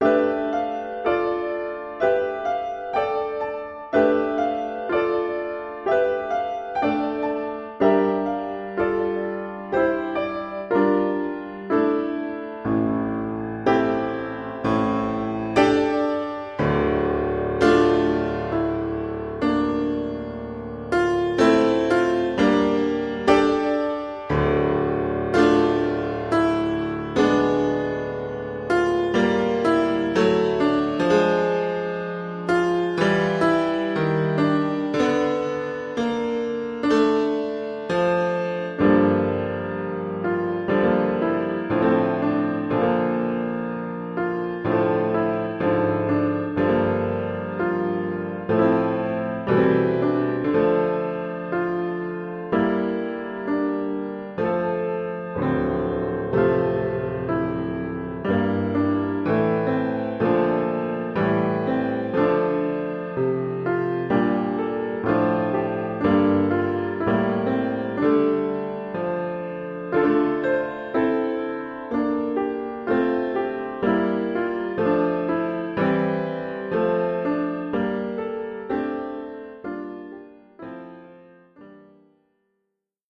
Oeuvre pour piano solo.